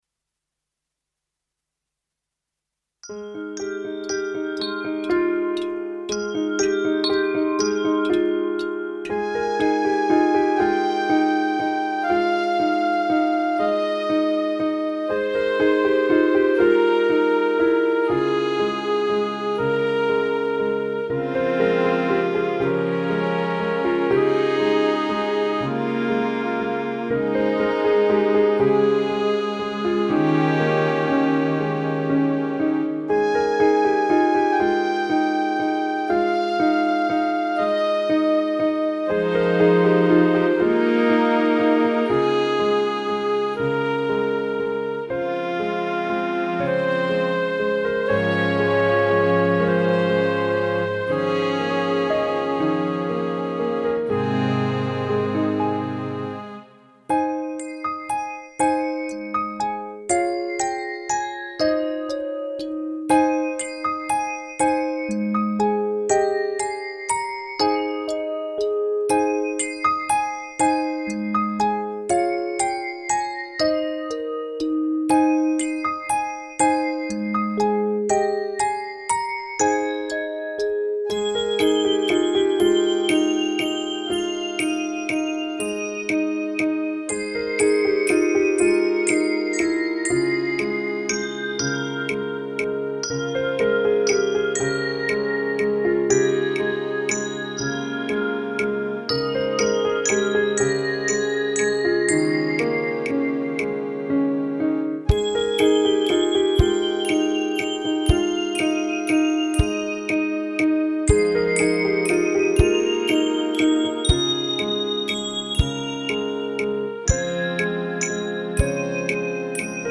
出来れば夜空でも見ながらぼ〜っと聞いて頂ければ嬉しいものです。
虚脱系の曲ですからあまり期待はしないでください。